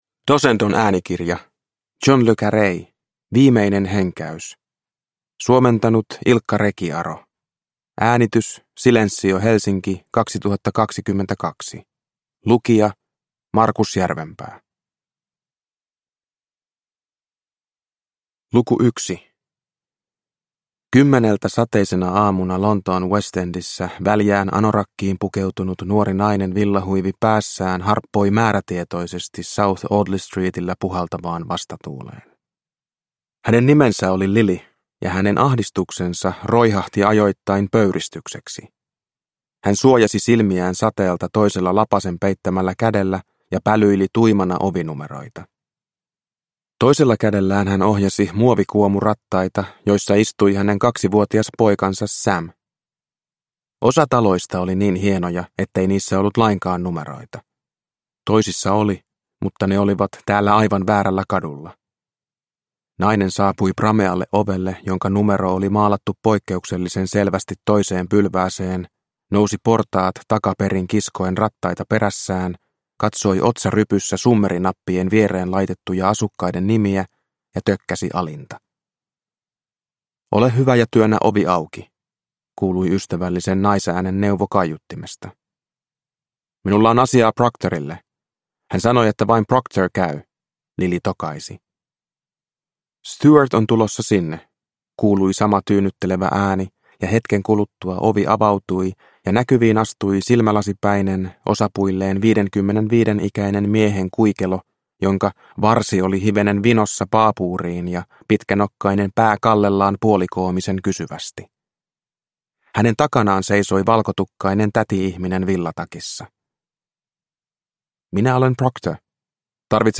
Viimeinen henkäys – Ljudbok – Laddas ner